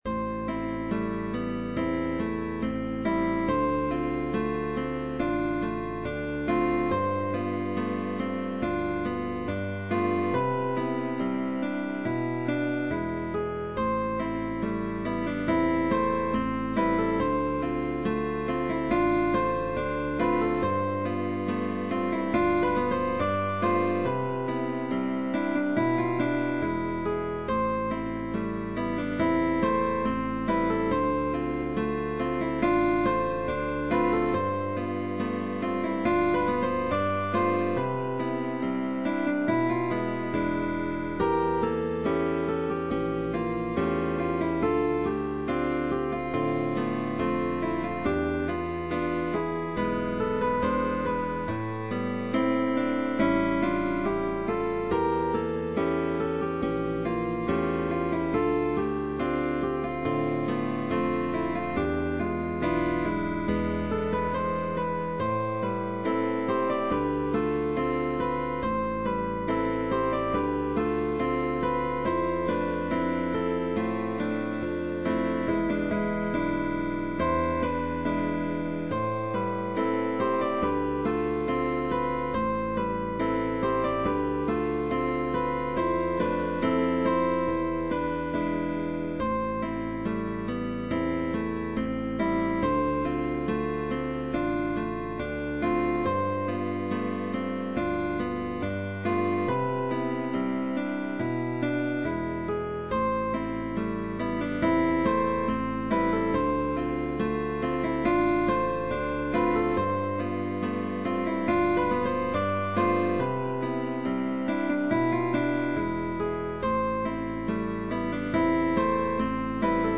Arpegios y acordes.
Pop Songs